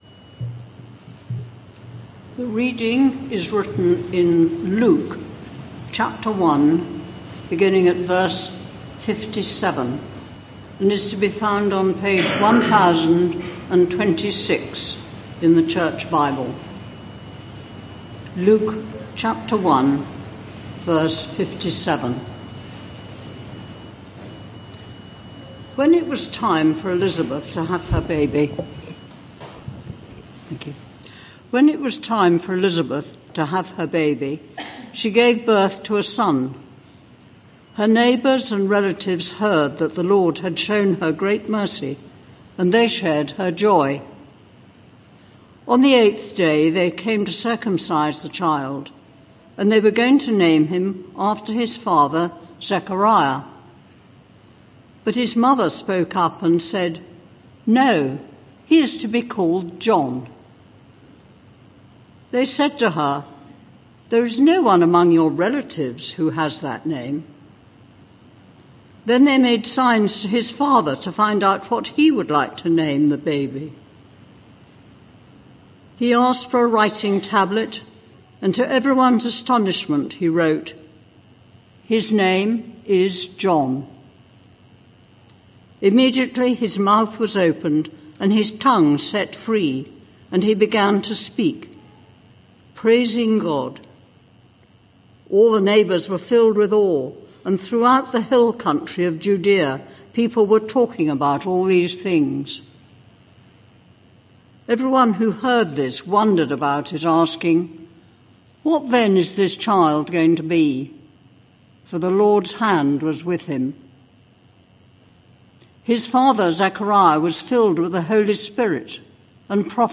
This sermon is part of a series: 7 December 2025